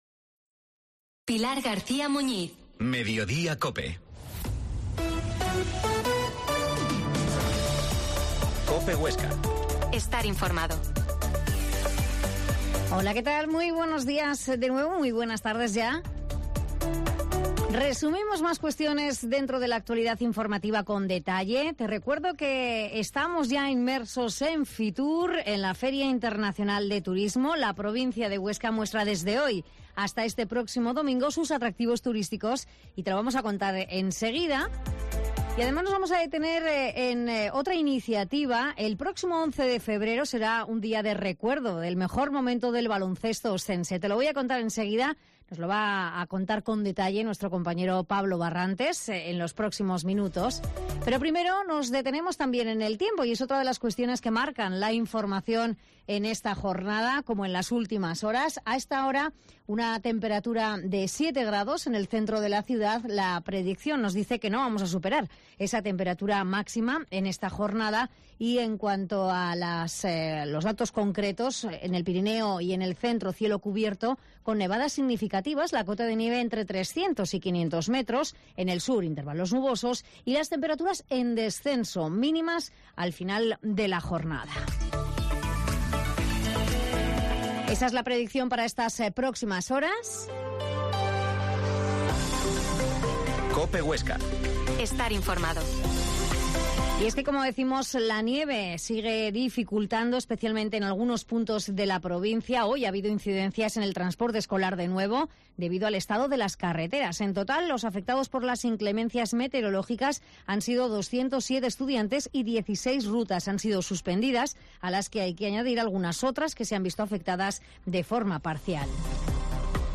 Mediodia en COPE Huesca 13.50h Reportaje de FITUR